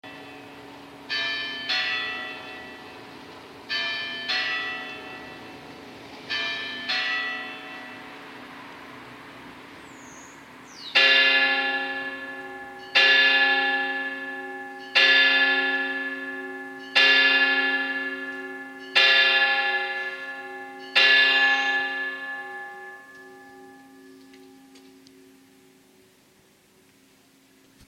Sonido-campanas.mp3